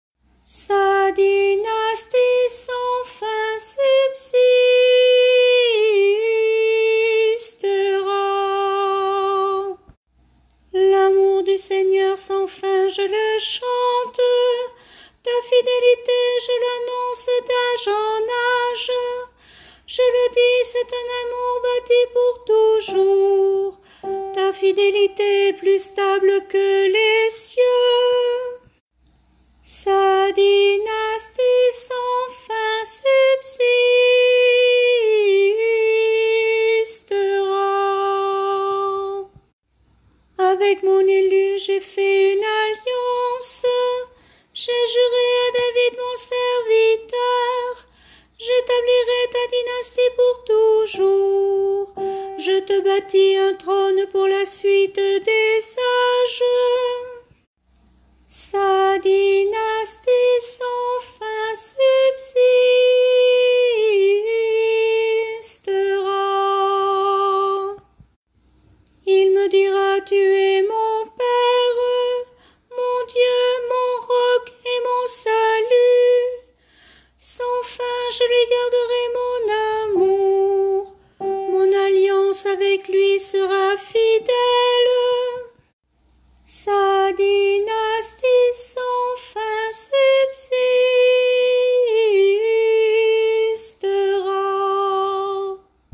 Chorale psaumes année A – Paroisse Aucamville Saint-Loup-Cammas